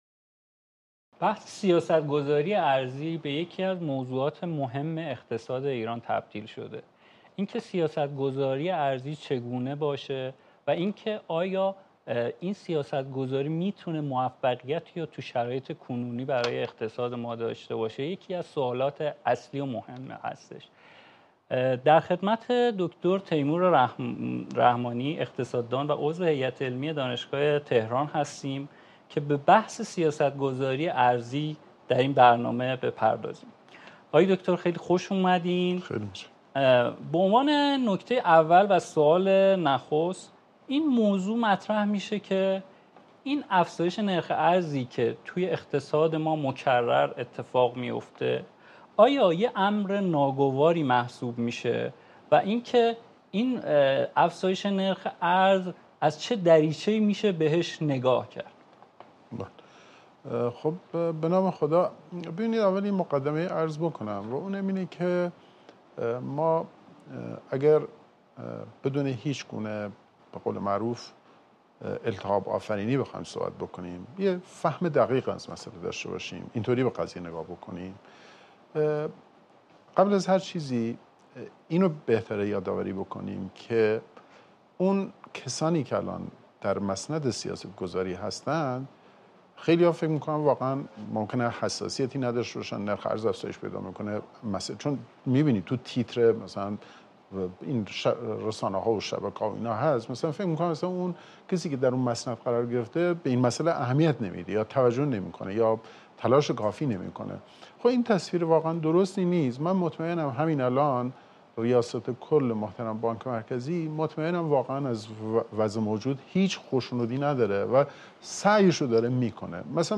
در آخرین میزگرد «اکوایران»